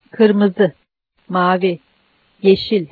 All words that indicate color take their accent on the last syllable.